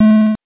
powerup_25.wav